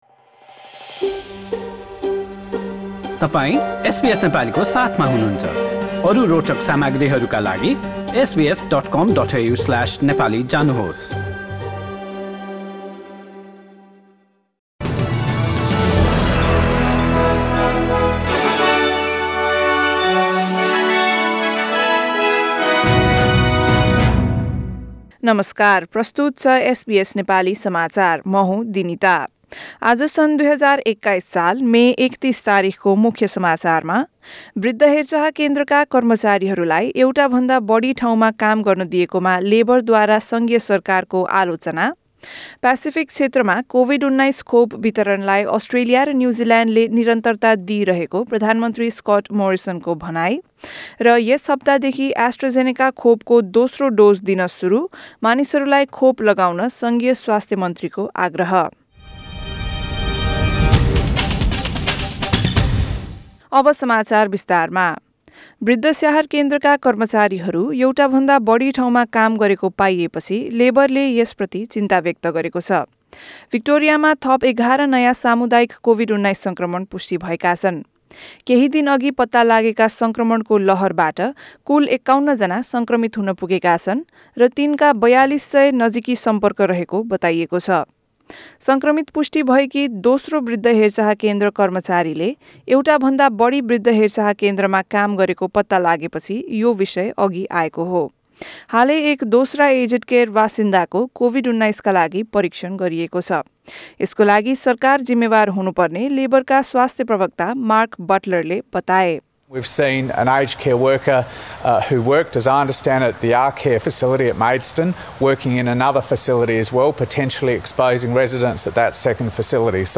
एसबीएस नेपाली अस्ट्रेलिया समाचार: सोमबार ३१ मे २०२१